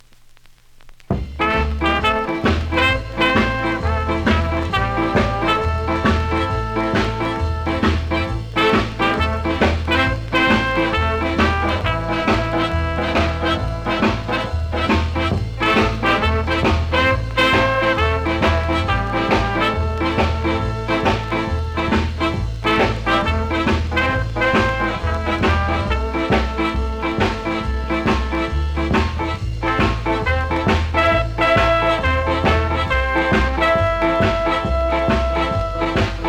CONDITION：見た目VG、音はVG+
両面キズはそこそこありますがノイズはそれほどでもないので試聴で確認下さい。